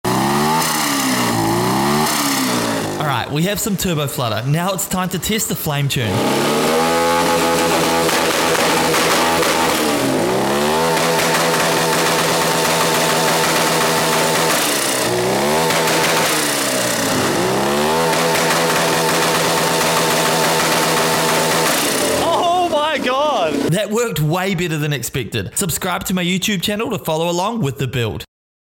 TURBO 150cc Drift Go Kart Sound Effects Free Download